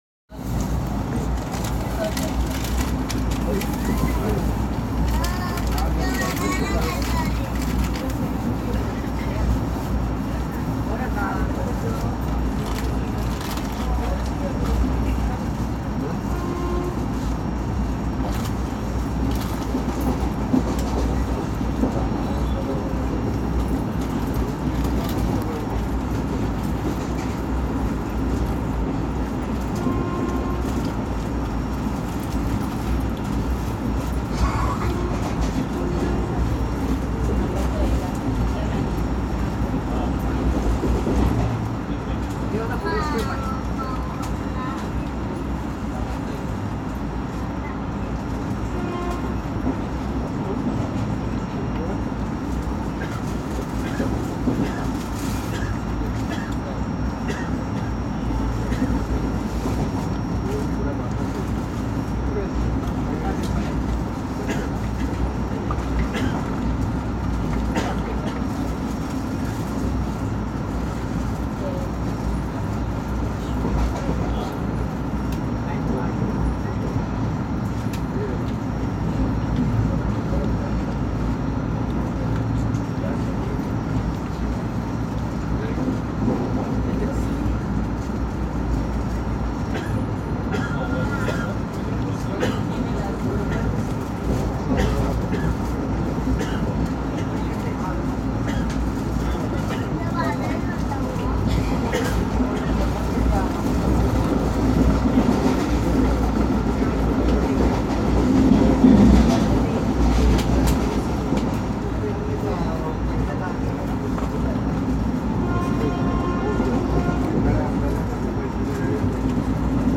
The departure horn was interesting – there was a shrill as well as melodious HT horn mixed with an LT horn!
The shrill HT horn and the LT horn were used more often; with the melodious HT horn being used occasionally.
After a stop of 3 mins, the LP decided to set the pace right away; and we blasted through Bhivpuri Road with continuous and melodious HT honking.